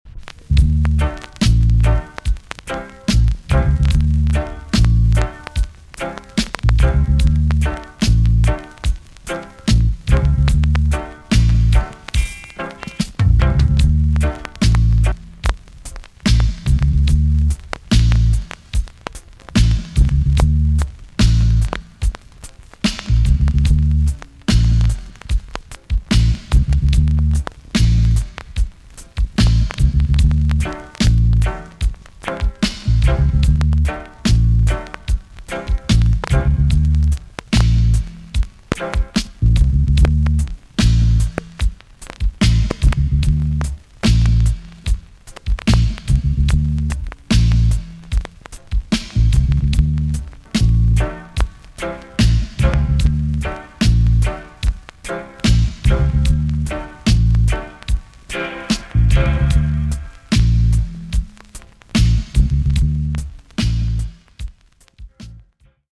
TOP >80'S 90'S DANCEHALL
B.SIDE Version
VG+ 軽いプチノイズがあります。